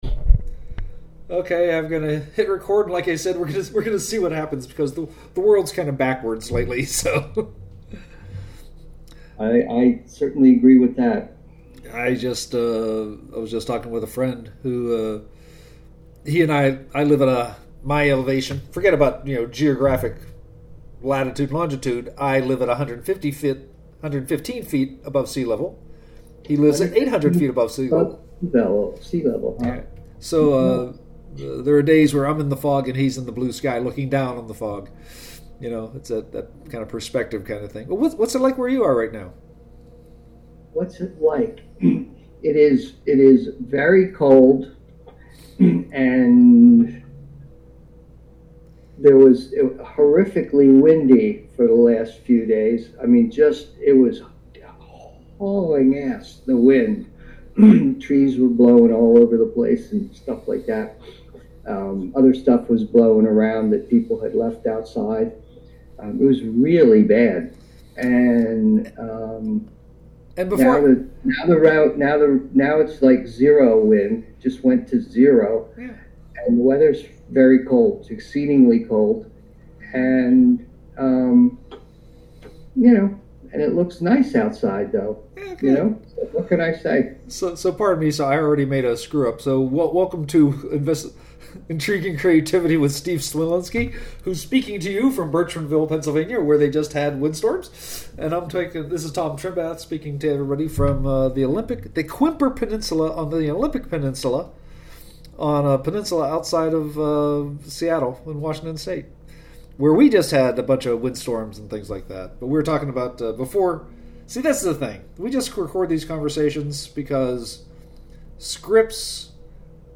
We had plans (but never a script), said hello, started talking, and never got around to our original idea.